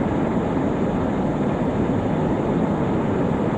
autopilotloop.ogg